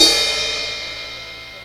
DJP_PERC_ (13).wav